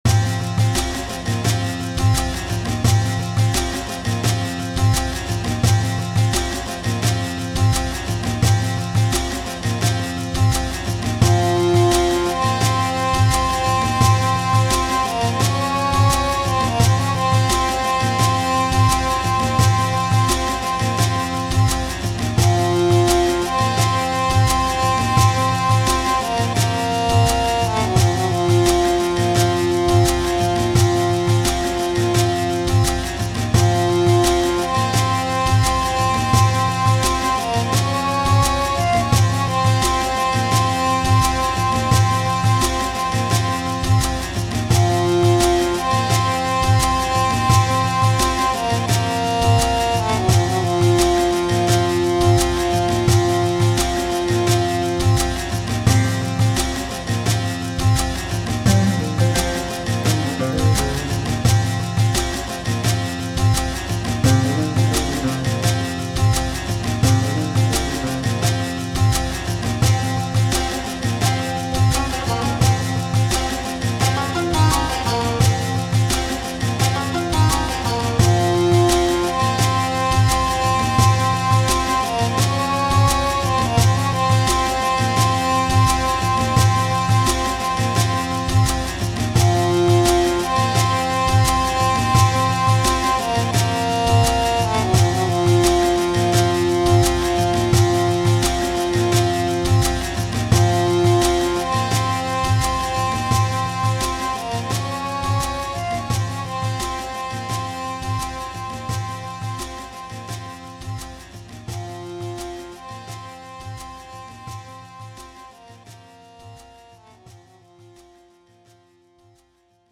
タグ: フィールド楽曲 民族音楽 砂漠 コメント: 延々と続く砂漠をイメージしたBGM。